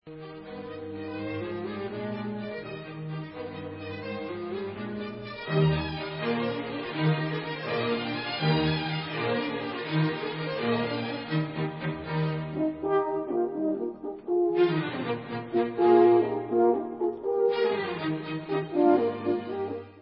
Sextet pro smyčcové kvarteto a 2 lesní rohy Es dur, op. 81 b